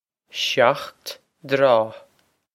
shocked dtraw
This is an approximate phonetic pronunciation of the phrase.